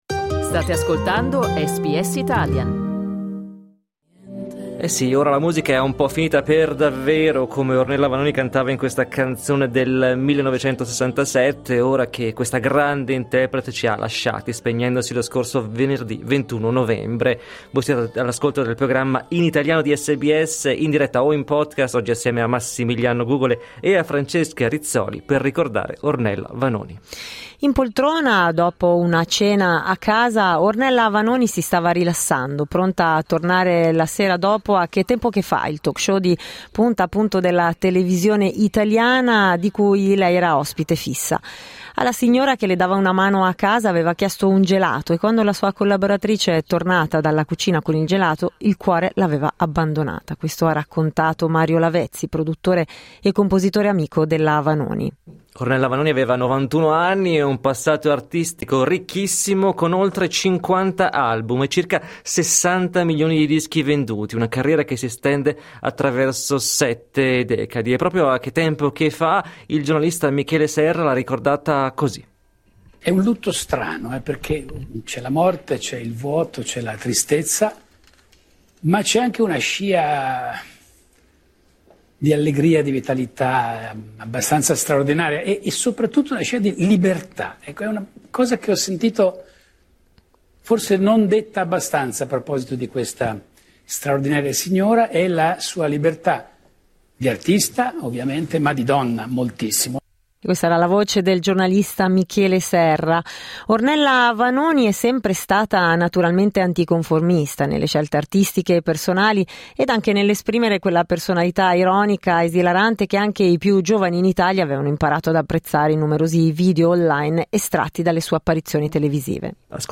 La celebre interprete ci ha lasciati venerdì 21 novembre: la sua umanità ed il suo talento musicale, rievocati da alcuni suoi collaboratori e dai nostri ascoltatori, nel ricordo che abbiamo dedicato ad Ornella Vanoni.